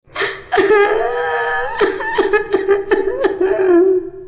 witch_llora_2.wav